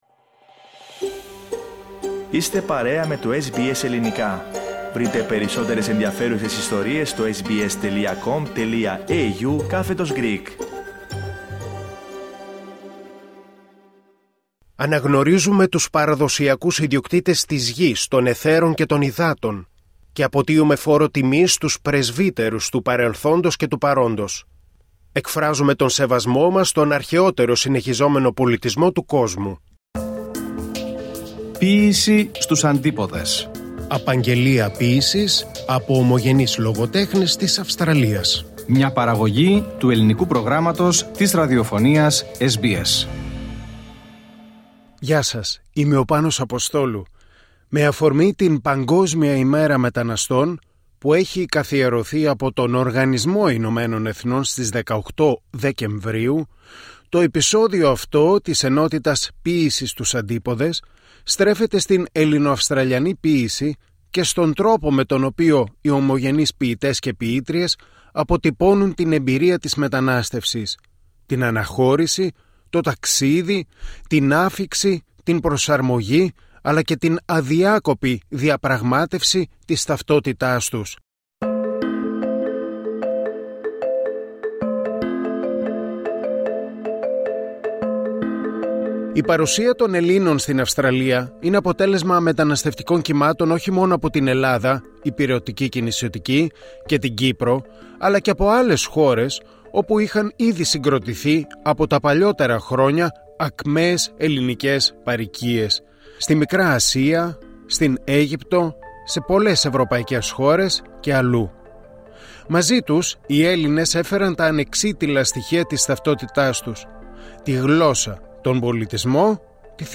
Σε αυτό το επεισόδιο ακούμε ποιήματα ομογενών με θέμα την μετανάστευση με αφορμή την Παγκόσμια Ημέρα Μεταναστών που έχει καθιερωθεί από τον Οργανισμό Ηνωμένων Εθνών για τις 18 Δεκεμβρίου